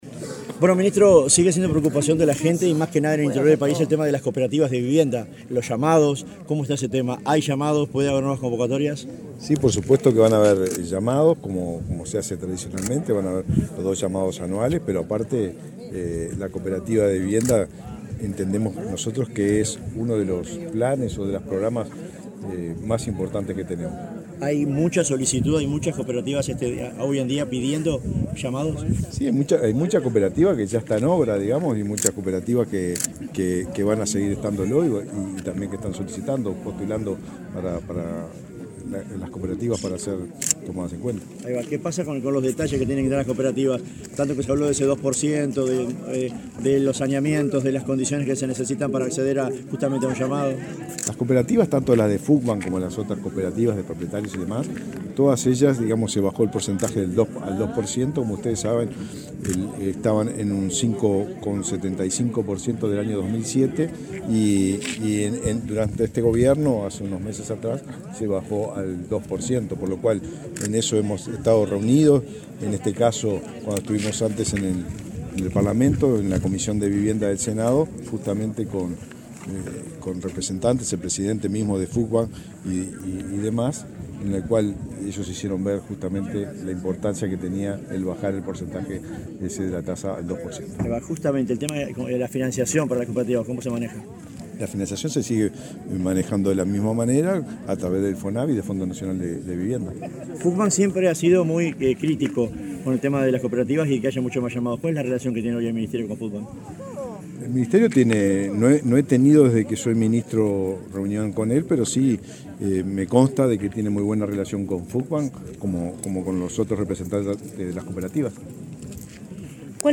Declaraciones del ministro de Vivienda, Raúl Lozano
Luego efectuó declaraciones a la prensa.